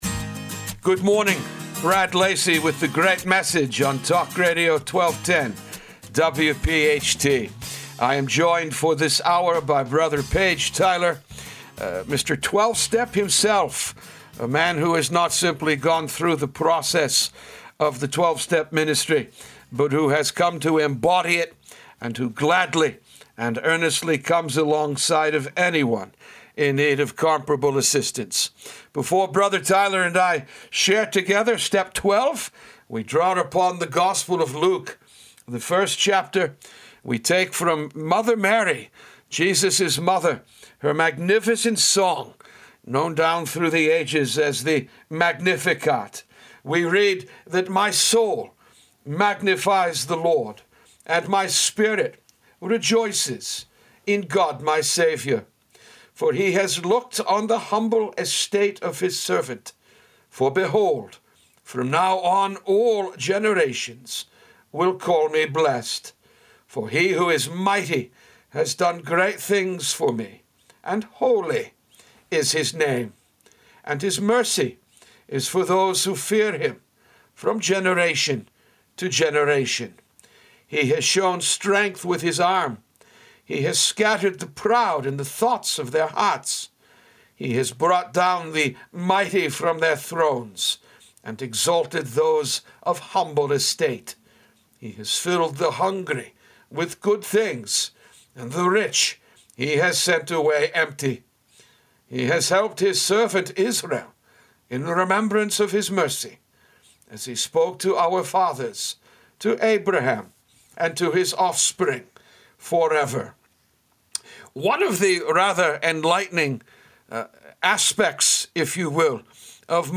The Great Message A Conversation